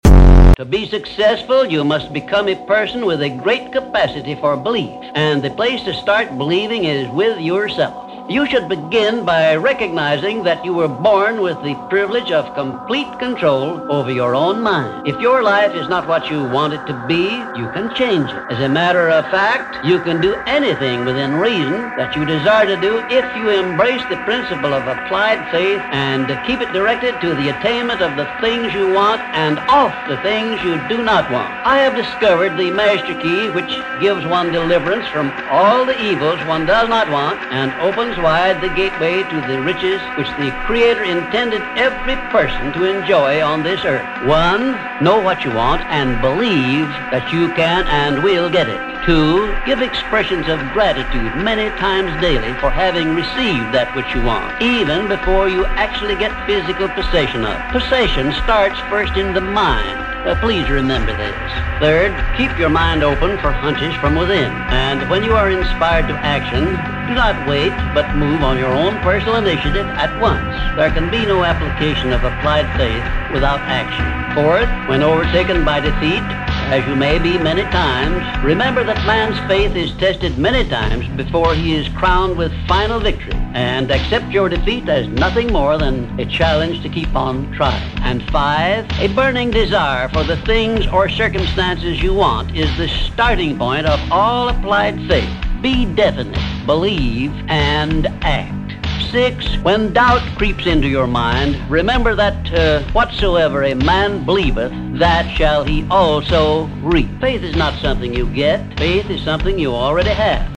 There are master keys to success and most people never use them. Speaker: Napoleon Hill